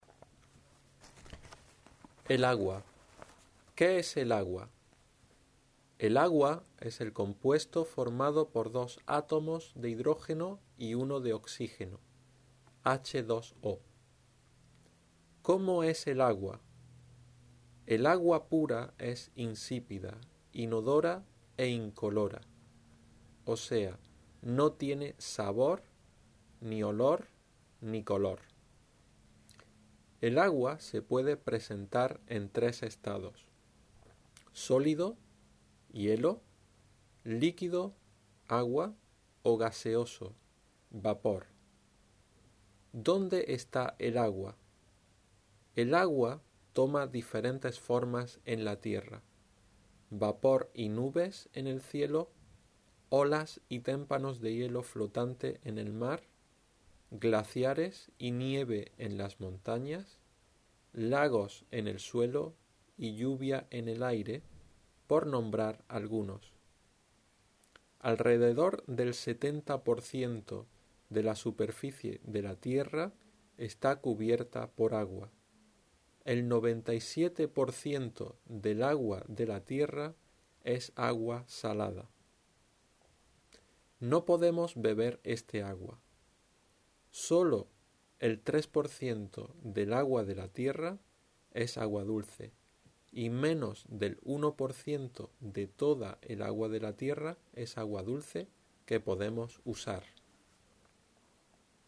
ElAgua_EjercicioAuditivo_SL.wav